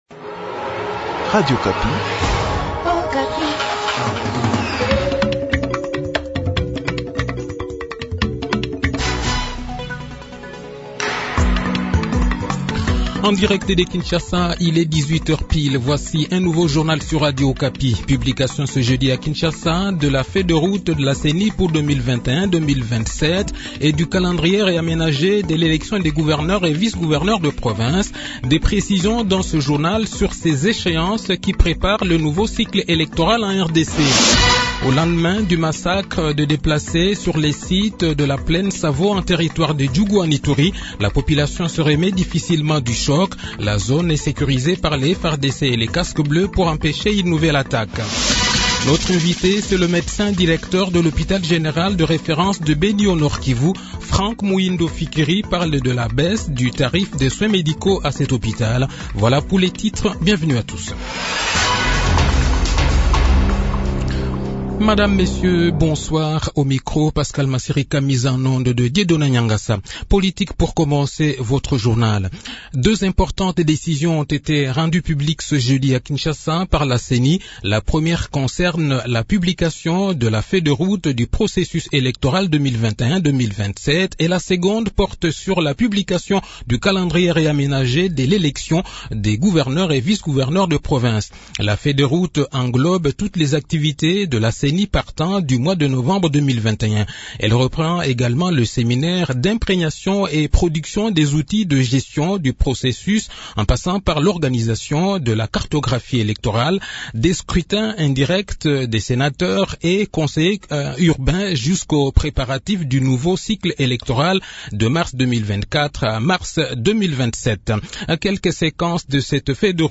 Le journal de 18 h, 3 fevrier 2022